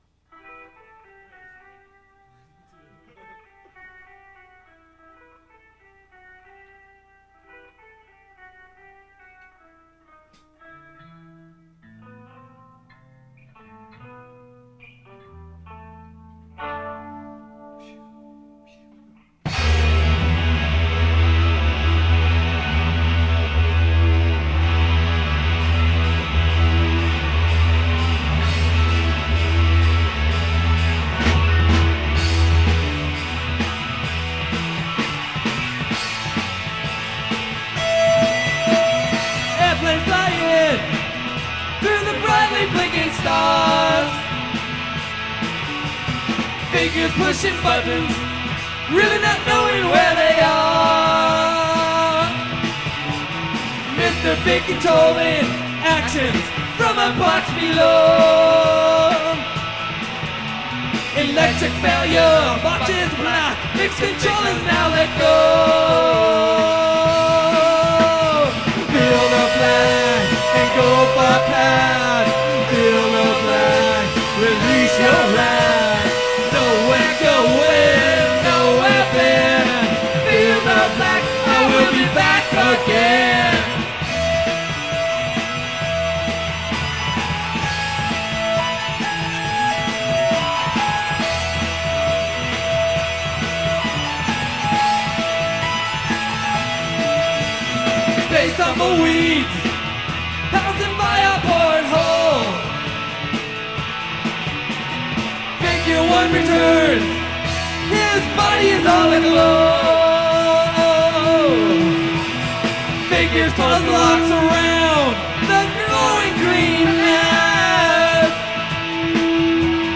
It’s also the slowest tempo of the song ever recorded.
FieldoBlack_PainfulKeyboards.mp3